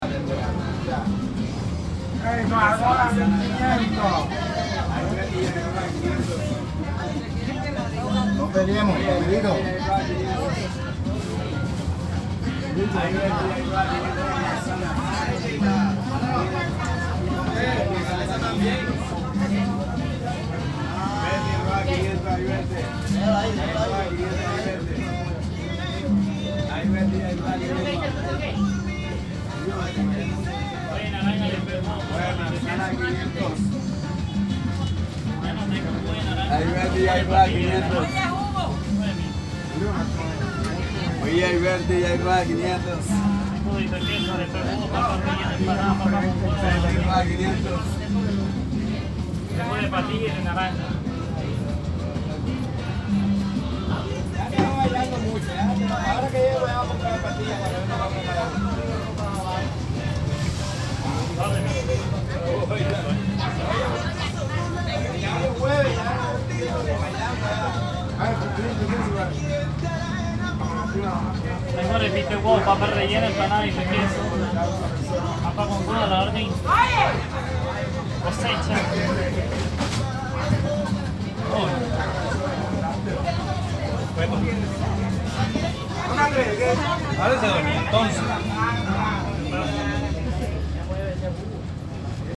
J'ai oublié d'enregistrer le son des vagues, je mets donc l'ambiance sonore du bus qui nous a amené ici.